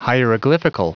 Prononciation du mot hieroglyphical en anglais (fichier audio)
Prononciation du mot : hieroglyphical